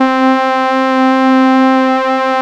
LONE PAD.wav